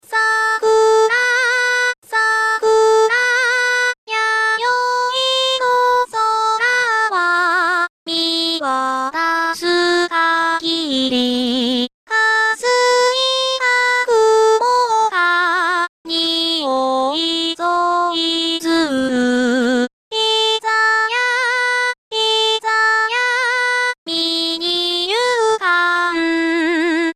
５分DTM
５分で歌わせてみた。
歌詞入れて音程合わせて組み込みツール使っただけです。